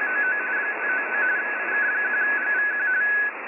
ham_radio_rds_msg.ogg